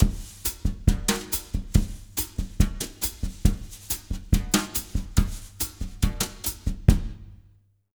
140BOSSA01-L.wav